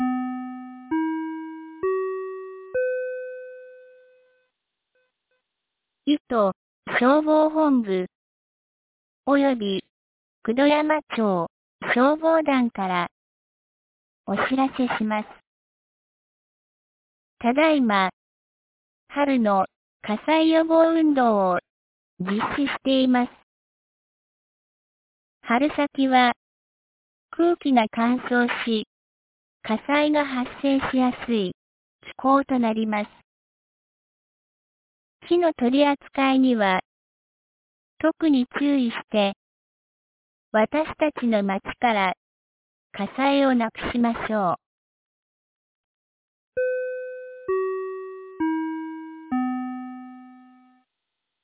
2025年03月01日 12時10分に、九度山町より全地区へ放送がありました。
放送音声